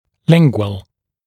[‘lɪŋgwəl][‘лингуэл]язычный, лингвальный